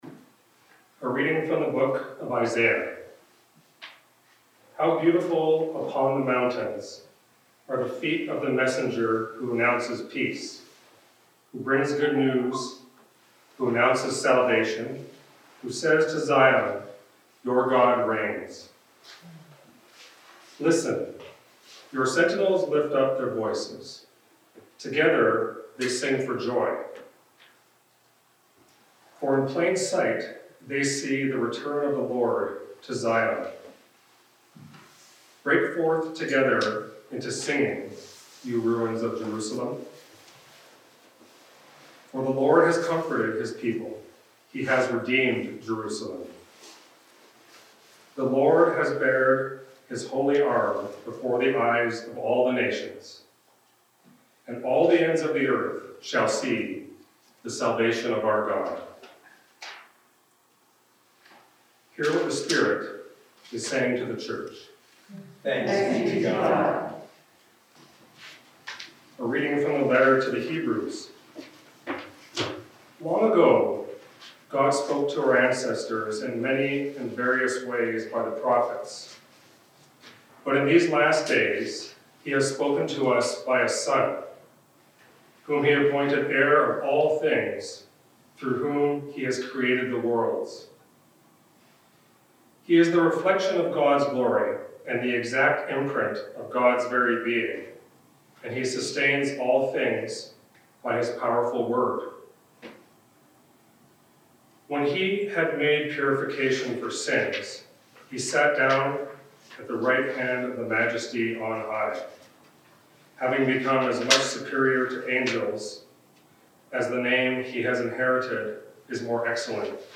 Candlelight Christmas Service 2021
NOTE: due to a technical glitch, the audio for the readings is not as clear as it should have been.